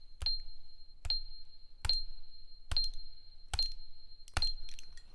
1. Задержка конечно присутствует и составляет ориентировочно 40...50 мсек;